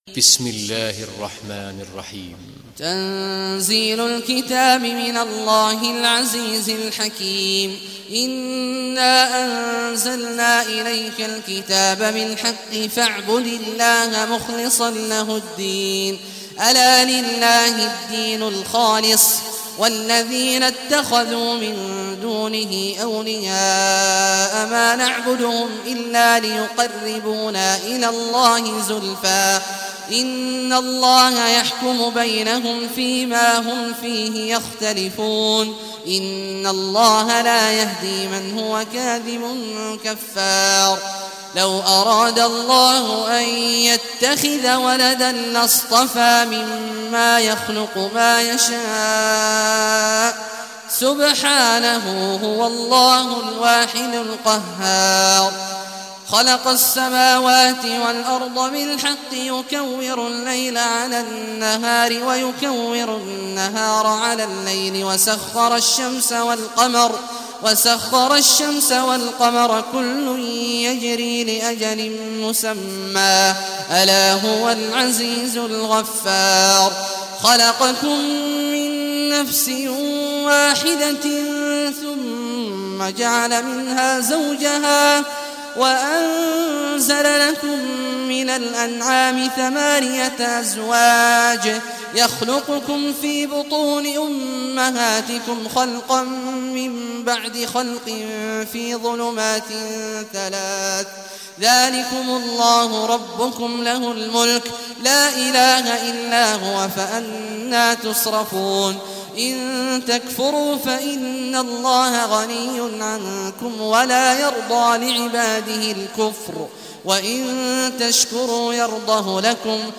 Surah Zumar Recitation by Sheikh Awad al Juhany
Surah Zumar, listen or play online mp3 tilawat / recitation in Arabic in the beautiful voice of Sheikh Abdullah Awad al Juhany.